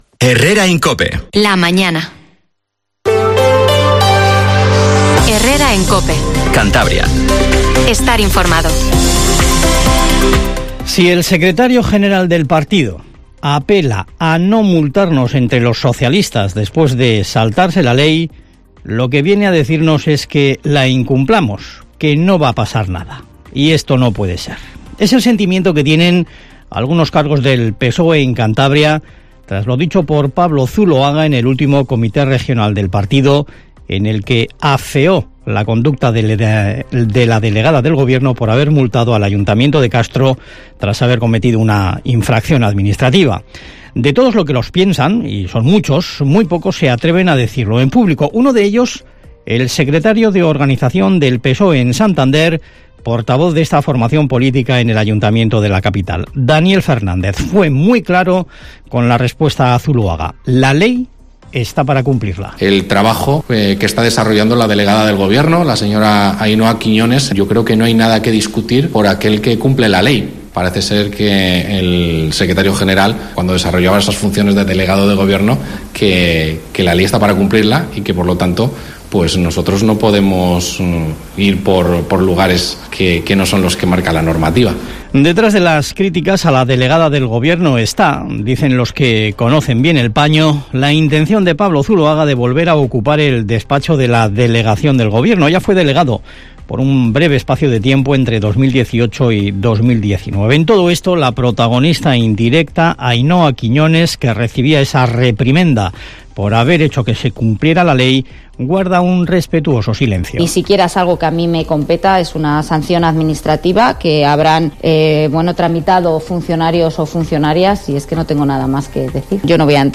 Informativo HERRERA en COPE CANTABRIA 07:50